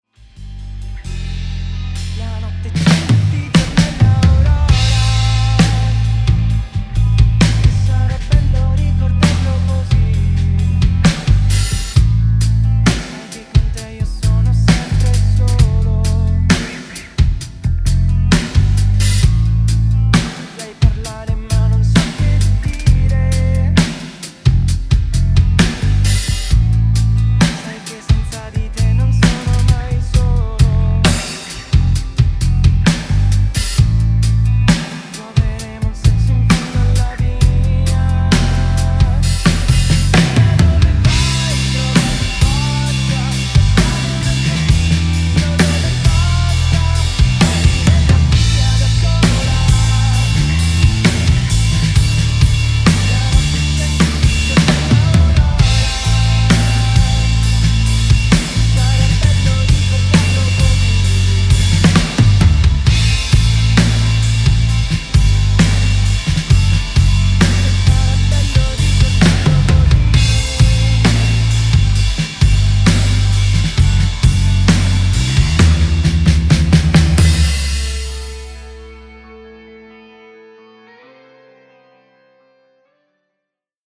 DRUMS_BASS
DRUMS_BASS.mp3